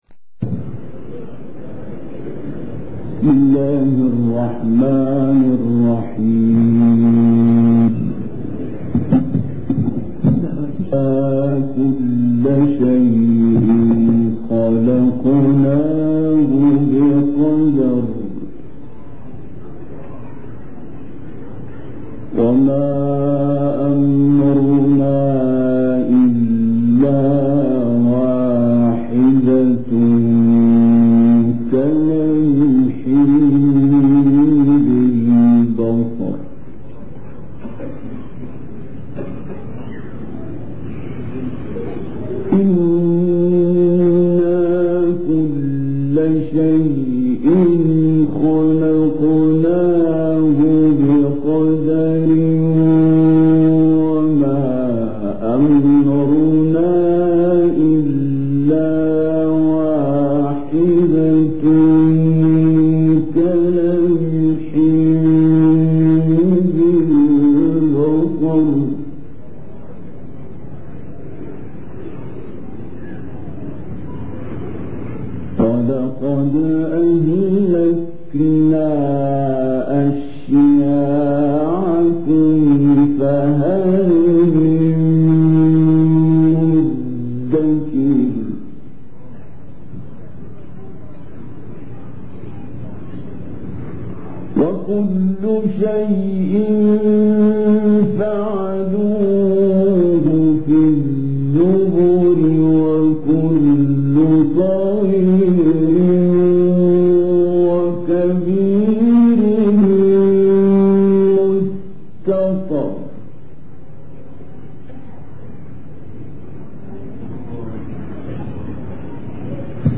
Rare recording from USA